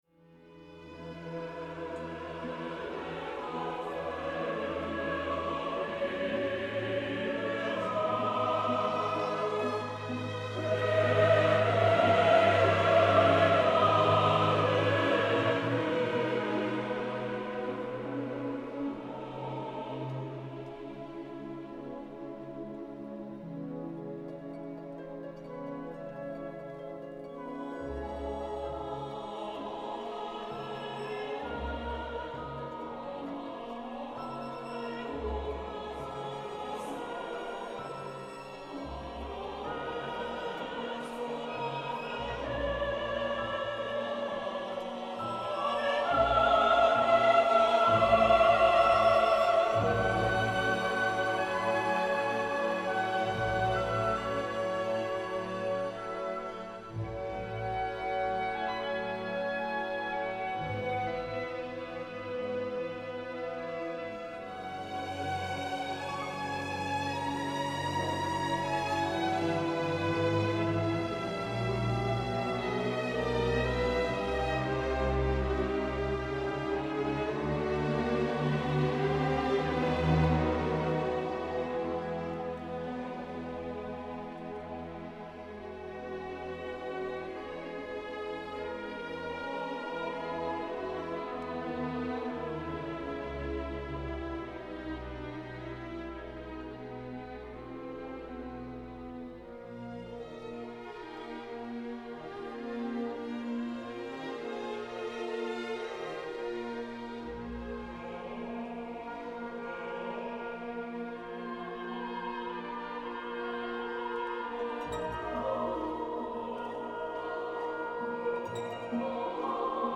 Of these, the third uses a chorus with text written by fellow Les Apaches member Michel-Dimitri Calvocoressi.